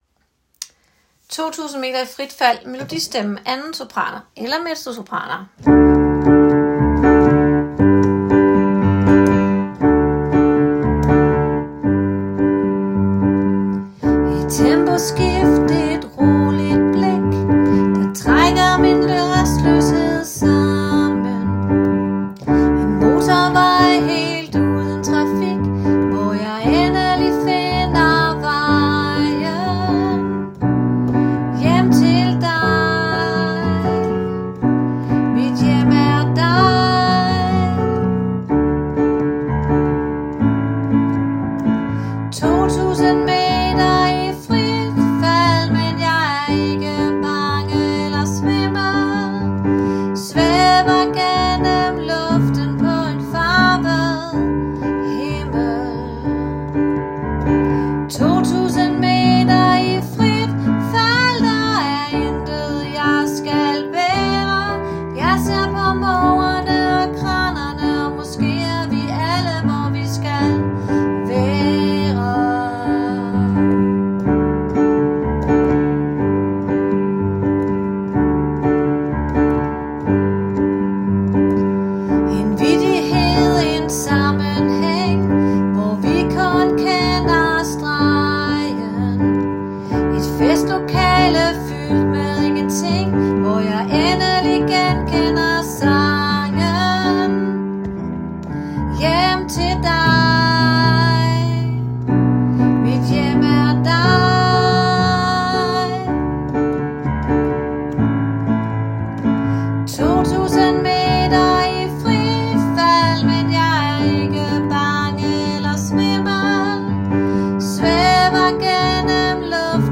2000 meter – 2. sopran